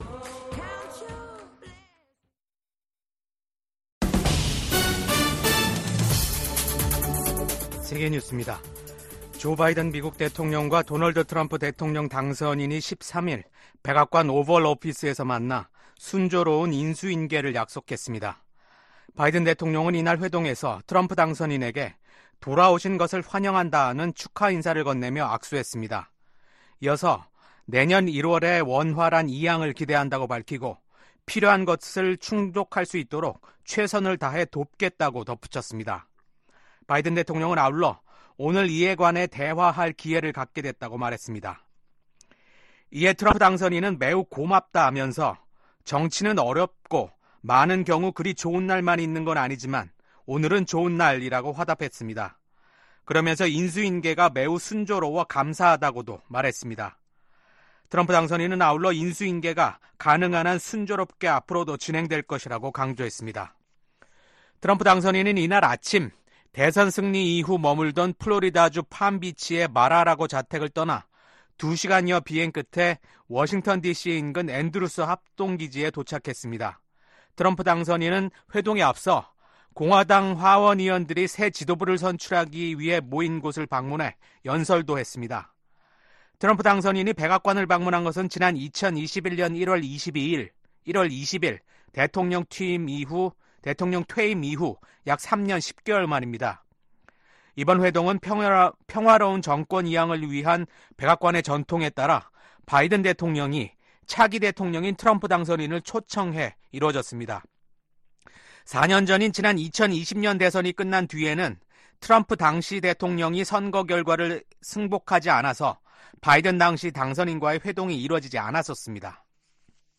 VOA 한국어 아침 뉴스 프로그램 '워싱턴 뉴스 광장'입니다. 미국 백악관이 북한군의 러시아 파병을 공식 확인했습니다. 최소 3천명이 러시아 동부 전선에 파병됐으며 훈련 뒤엔 우크라이나와의 전투에 배치될 가능성이 있다고 밝혔습니다.